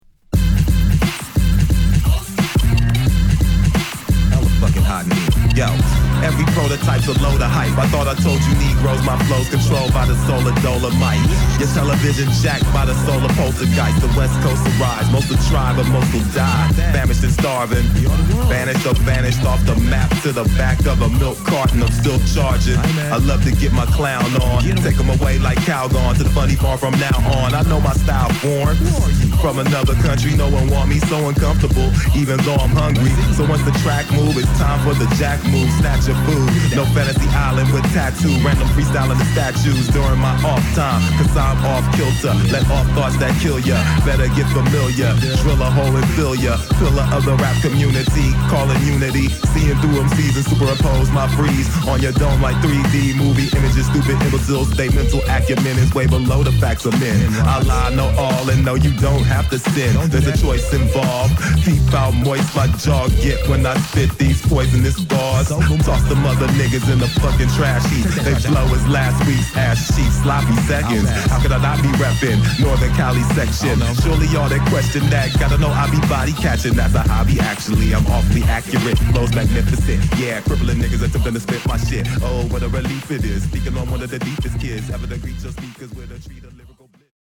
デジタルビートが炸裂したぶりぶりのヘビーサウンド！！